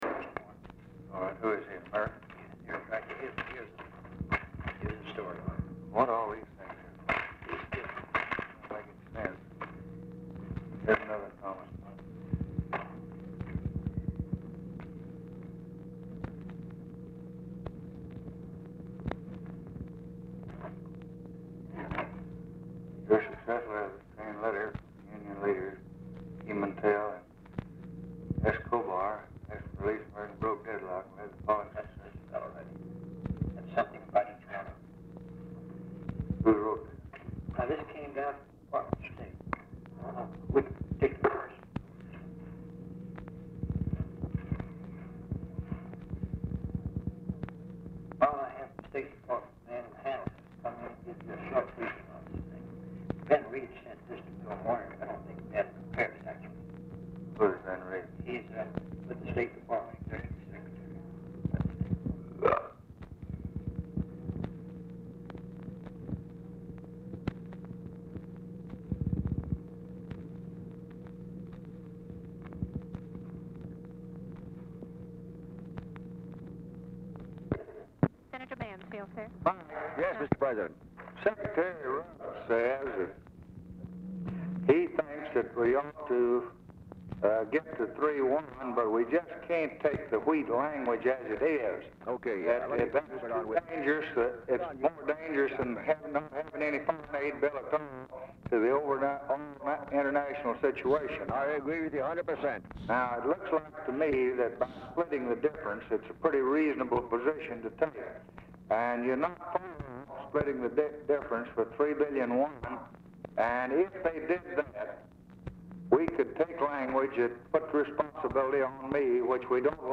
OFFICE CONVERSATION; TELEPHONE OPERATOR
Oval Office or unknown location
"#3"; 1:18 OFFICE CONVERSATION ABOUT RELEASED US HOSTAGES IN BOLIVIA PRECEDES CALL; LBJ IS APPARENTLY MEETING WITH JACK VALENTI AT TIME OF CALL; PREVIOUSLY OPENED 4/1994 AS TWO SEPARATE RECORDINGS
Telephone conversation
Dictation belt